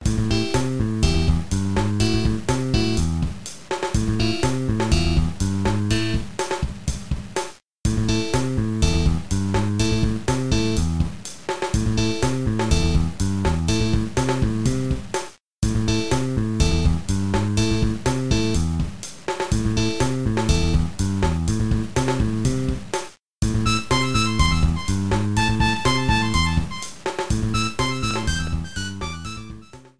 Trimmed and fade out